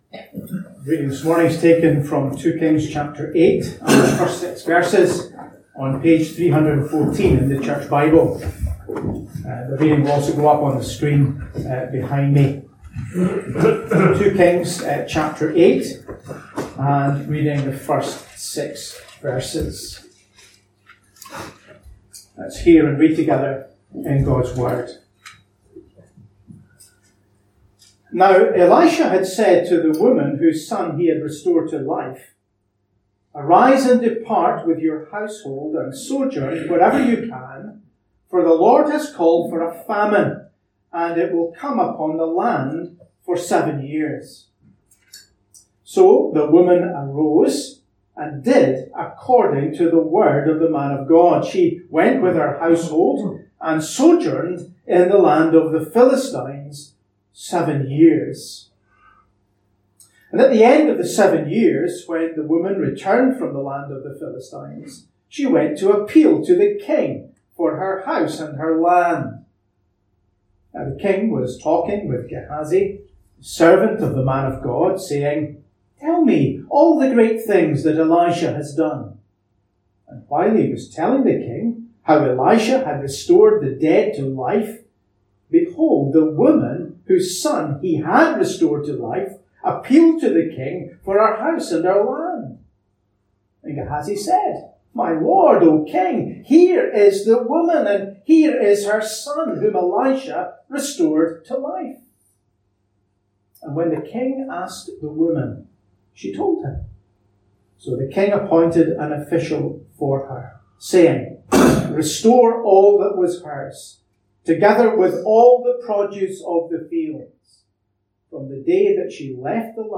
A link to the video recording of the 11:00am service and an audio recording of the sermon.